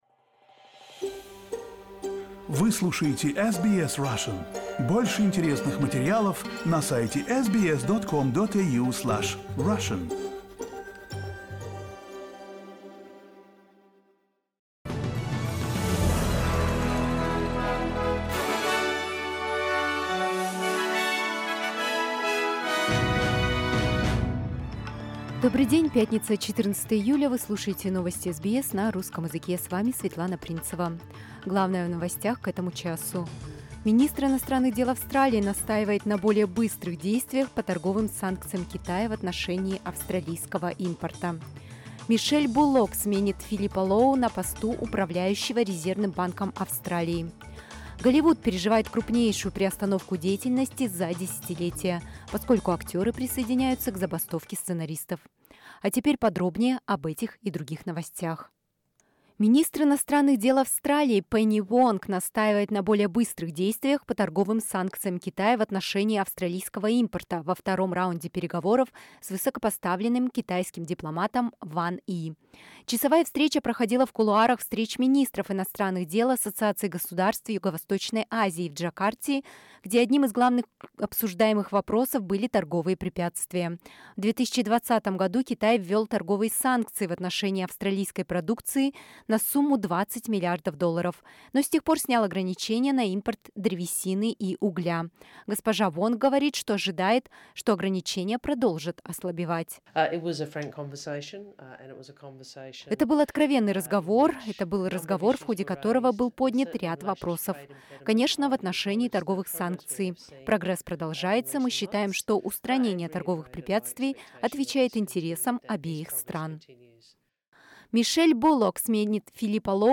SBS news in Russian — 14.07.2023